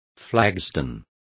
Complete with pronunciation of the translation of flagstones.